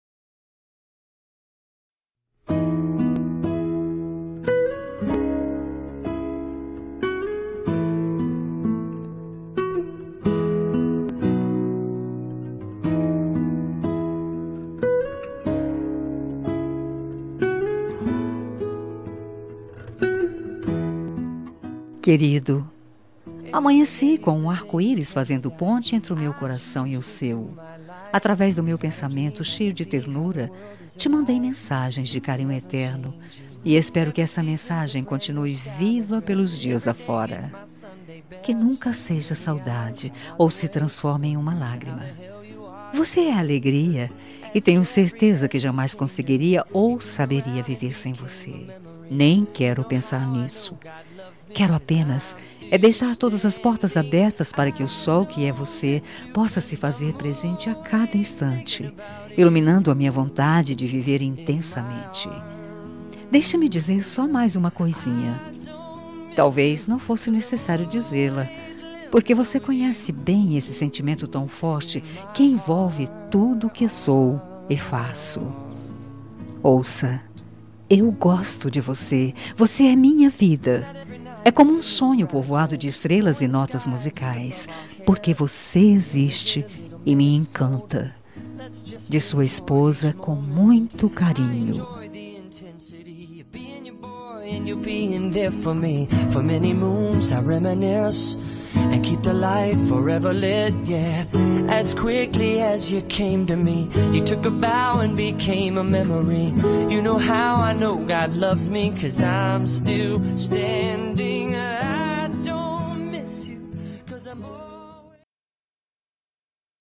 Telemensagem Romântica para Marido – Voz Feminina – Cód: 201824